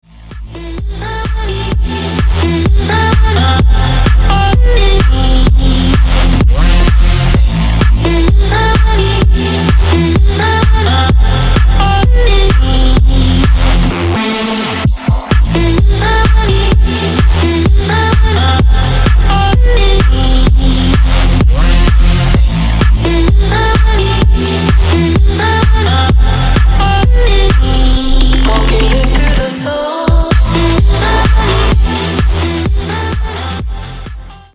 • Качество: 192, Stereo
громкие
Electronic
EDM
качающие
Стиль: trance